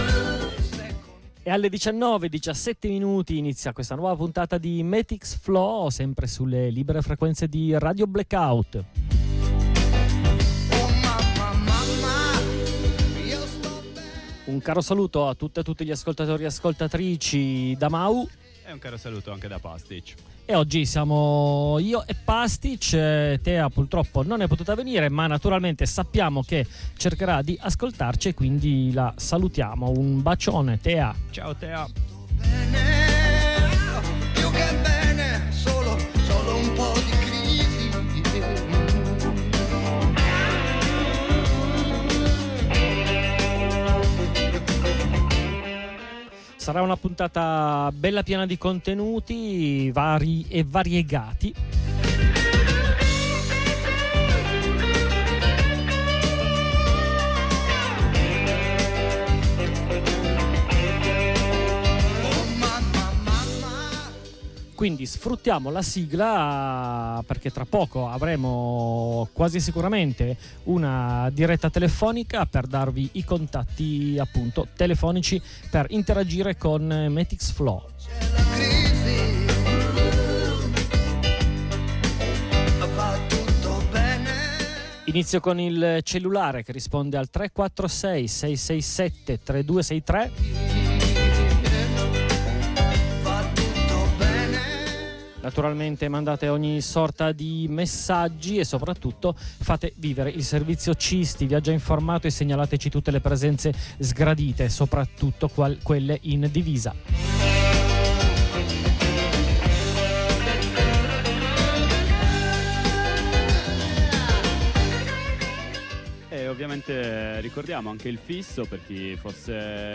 Nella prima parte della trasmissione di oggi abbiamo ospitato telefonicamente una compagna che si trova a Lahr, in Germania, per partecipare ad una lunga marcia organizzata dai curdi per domani 15 febbraio, giornata del complotto internazionale contro Abdullah öcalan. Alla fine della giornata di cammino, da Offenburg fino a Lahr, la polizia fascista tedesca, poco prima di entrare al centro culturale, ha attaccato violentemente i partecipanti e le partecipanti.